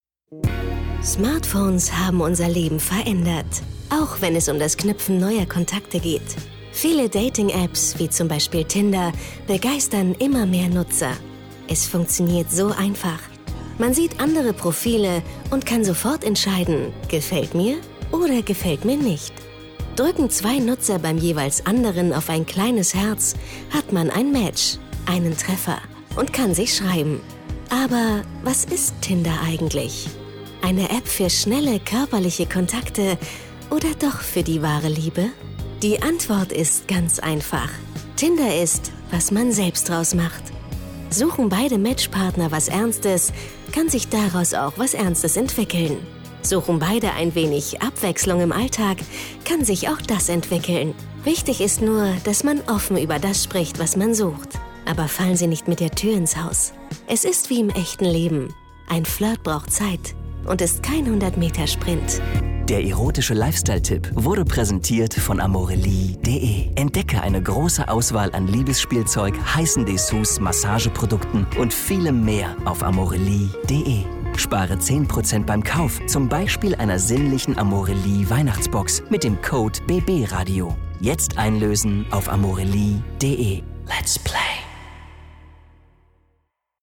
hell, fein, zart, markant, sehr variabel
Presentation